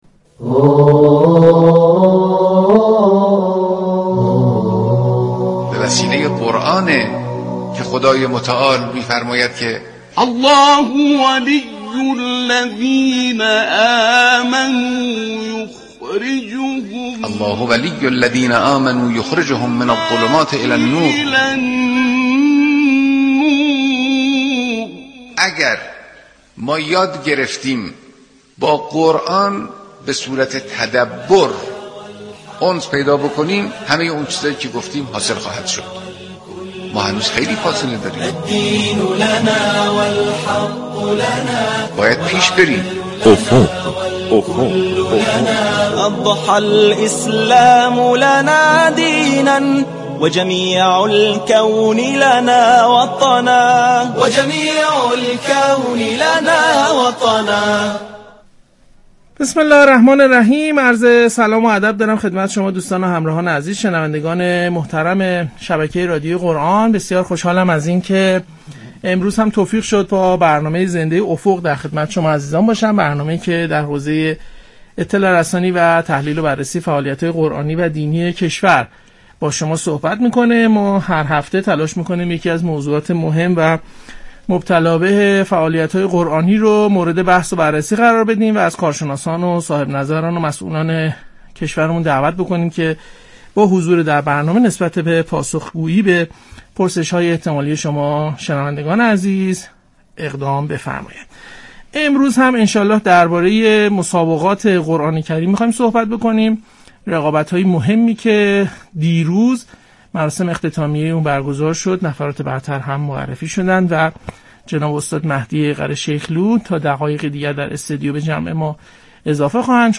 در گفتگو با برنامه زنده